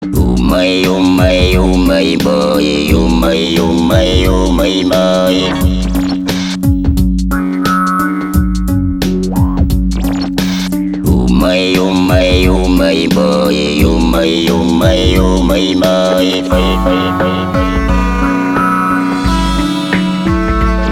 • Качество: 320, Stereo
Народные